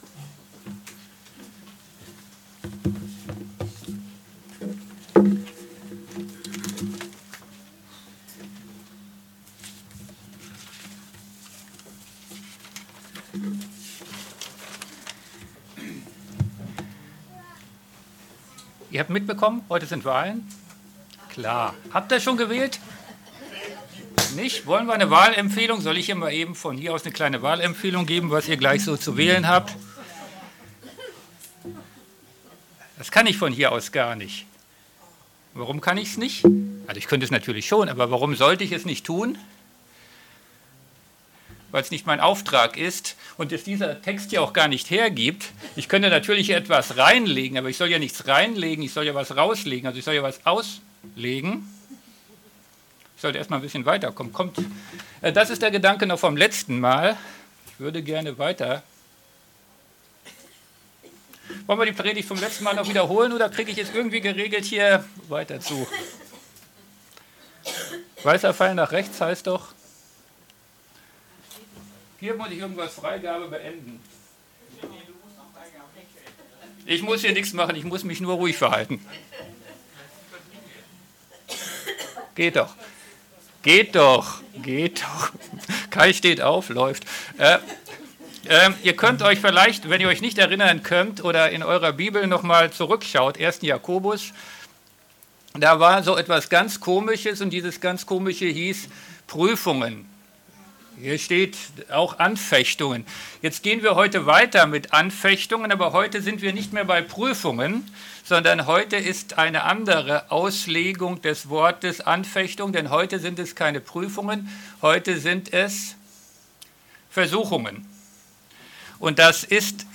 MP3 Predigten